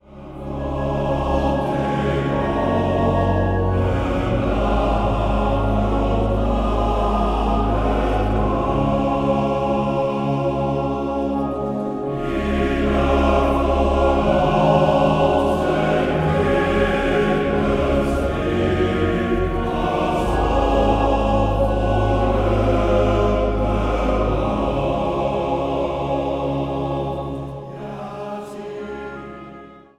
5 stemmen
Zang | Mannenkoor